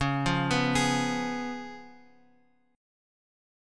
C#7 chord {x 4 3 4 2 x} chord